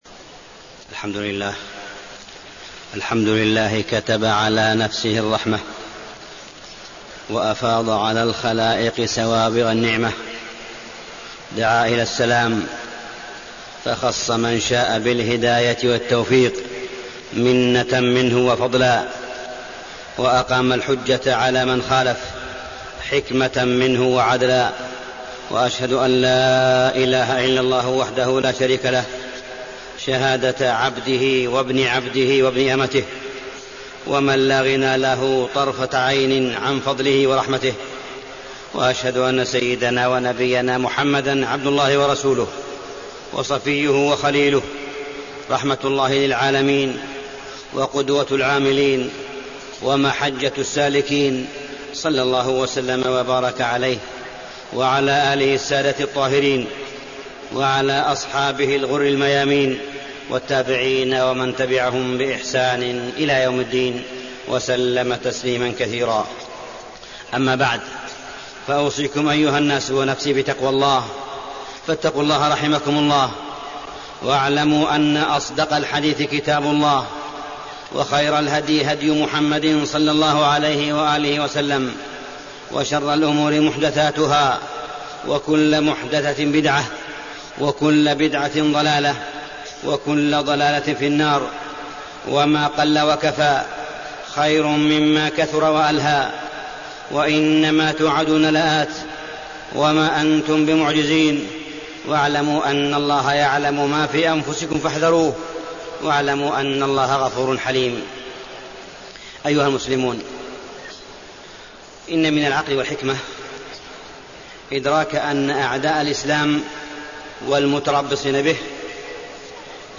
تاريخ النشر ٢٧ ربيع الأول ١٤٣٤ هـ المكان: المسجد الحرام الشيخ: معالي الشيخ أ.د. صالح بن عبدالله بن حميد معالي الشيخ أ.د. صالح بن عبدالله بن حميد أصول منهج السلف الصالح